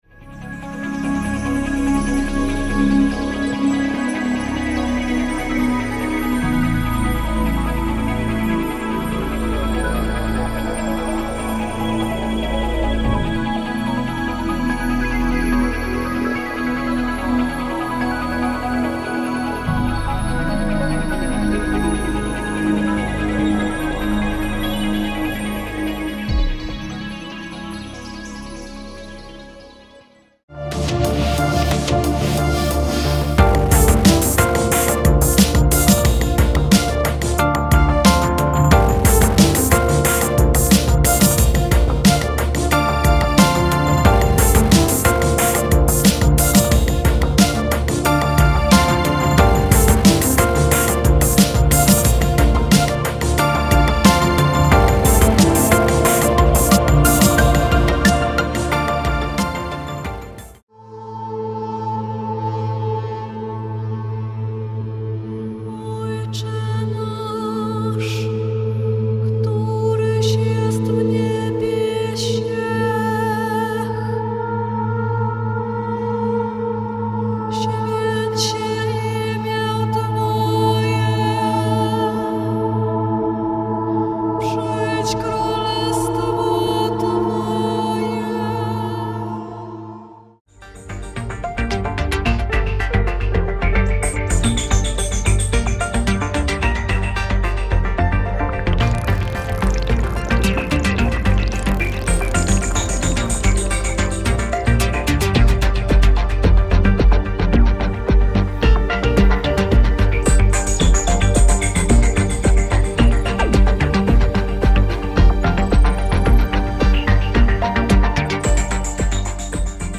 file under: all EM genres4/2011 | ~ 140 minutes